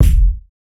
KICK BLAP.wav